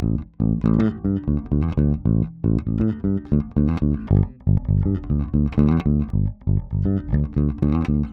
28 Bass PT2.wav